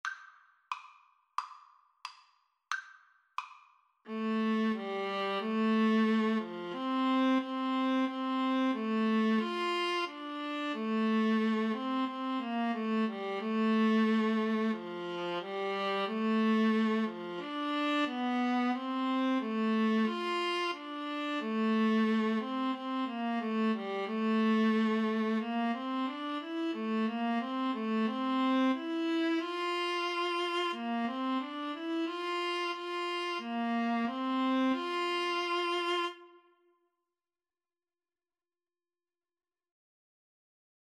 Play (or use space bar on your keyboard) Pause Music Playalong - Player 1 Accompaniment reset tempo print settings full screen
4/4 (View more 4/4 Music)
F major (Sounding Pitch) (View more F major Music for Clarinet-Viola Duet )